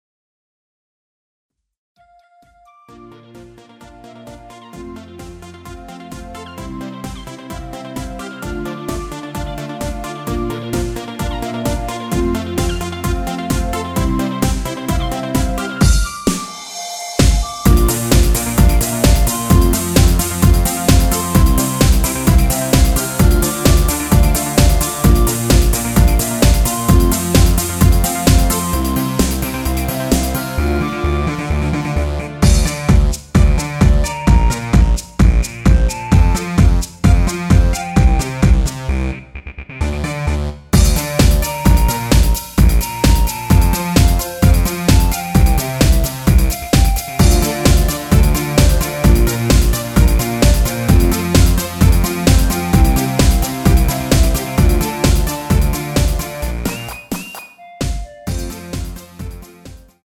원키에서(-1)내린 멜로디 포함된 MR입니다.
Dm
앞부분30초, 뒷부분30초씩 편집해서 올려 드리고 있습니다.
중간에 음이 끈어지고 다시 나오는 이유는